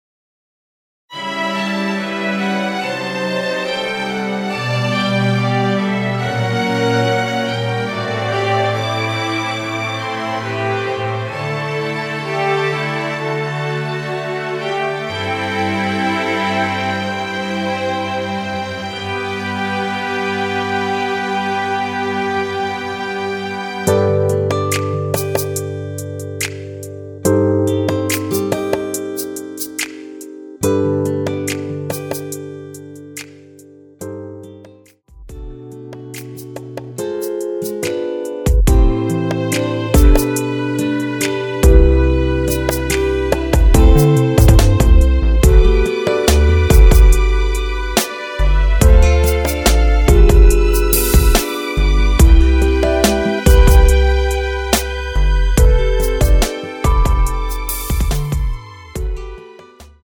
◈ 곡명 옆 (-1)은 반음 내림, (+1)은 반음 올림 입니다.
앞부분30초, 뒷부분30초씩 편집해서 올려 드리고 있습니다.
중간에 음이 끈어지고 다시 나오는 이유는
위처럼 미리듣기를 만들어서 그렇습니다.